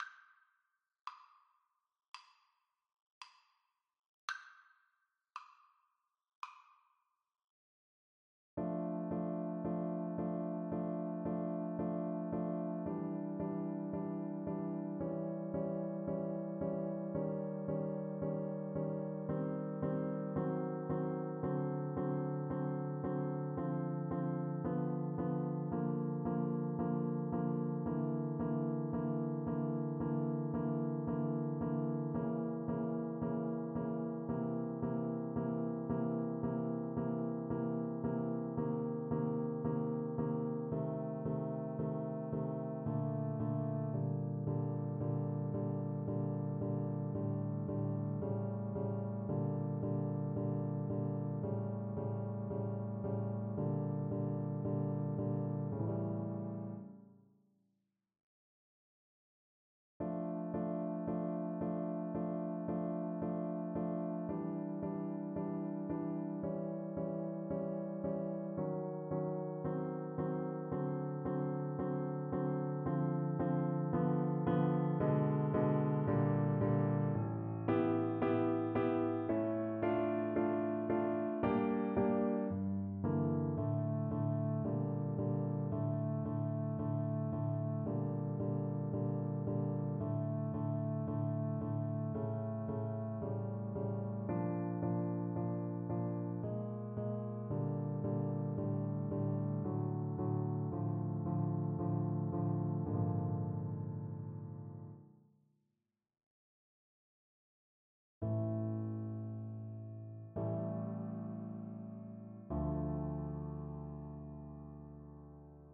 Free Sheet music for Piano Four Hands (Piano Duet)
Play (or use space bar on your keyboard) Pause Music Playalong - Player 1 Accompaniment transpose reset tempo print settings full screen
D minor (Sounding Pitch) (View more D minor Music for Piano Duet )
4/4 (View more 4/4 Music)
Largo = 56
Classical (View more Classical Piano Duet Music)